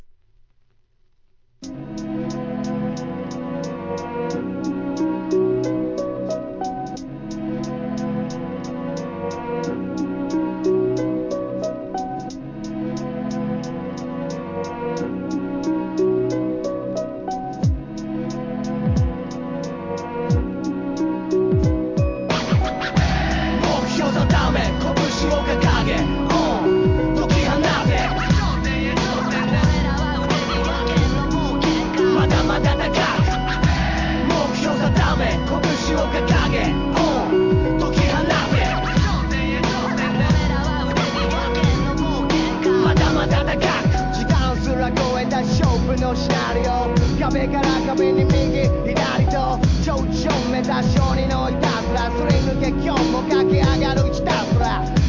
JAPANESE HIP HOP/R&B